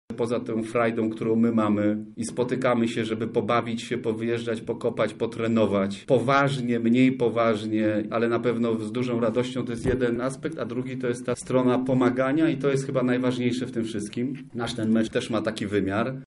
O inicjatywie opowiada jeden z uczestników meczu, aktor Jarosław Jakimowicz: